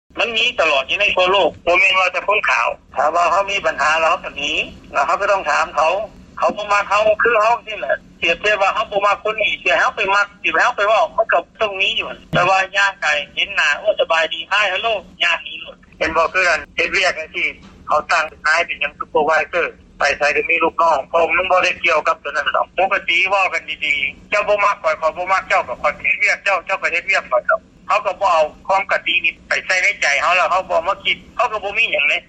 ການສໍາພາດປະຊາຊົນລາວ-ອາເມຣິກາຄົນນຶ່ງ